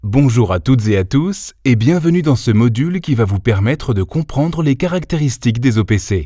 Voix off français grave posée jouée dynamique souriant home studio band annonce pub radio télé e learning
Sprechprobe: eLearning (Muttersprache):